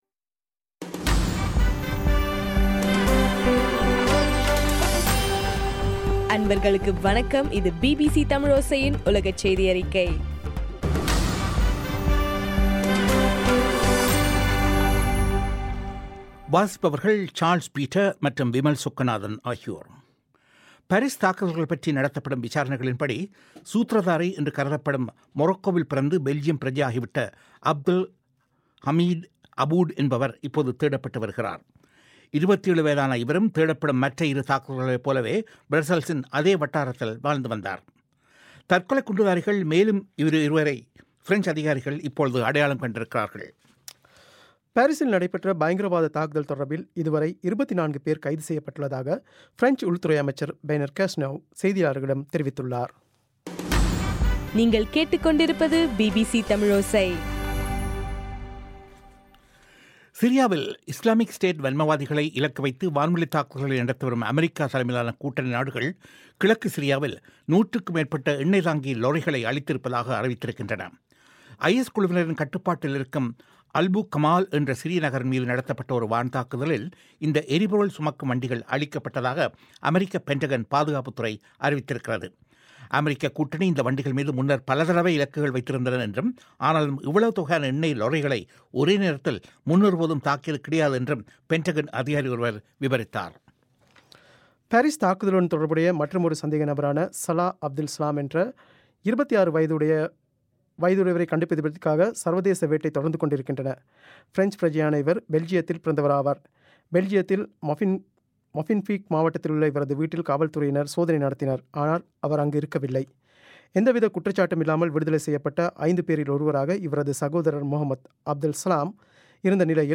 இன்றைய (நவம்பர் 16) பிபிசி தமிழோசை செய்தியறிக்கை